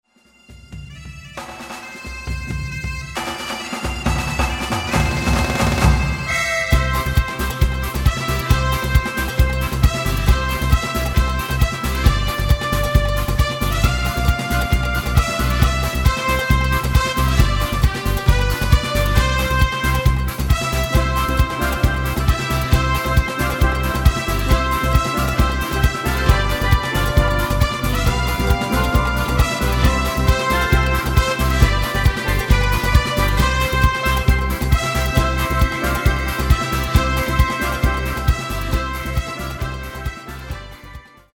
Midifile gearrangeerd in de stijl van:
Taal uitvoering: Instrumentaal
Genre: Carnaval / Party / Apres Ski
Originele song is instrumentaal